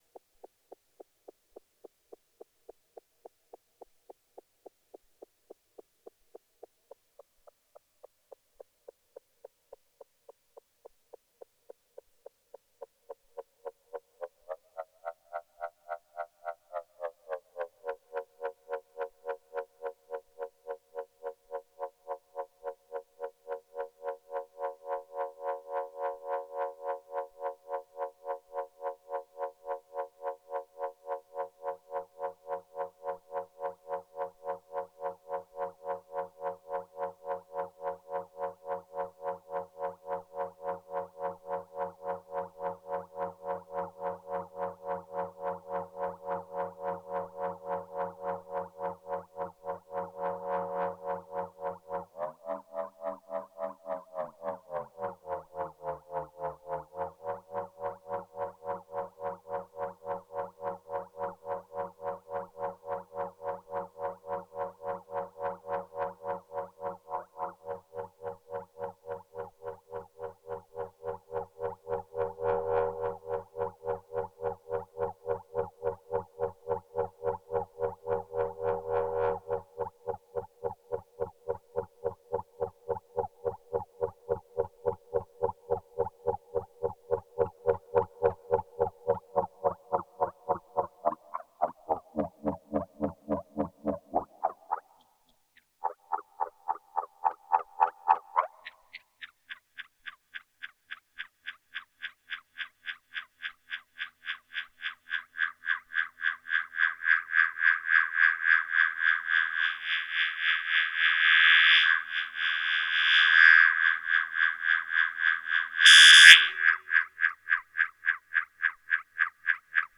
Petit solo de A100 avec 1 seul osc.